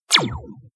laser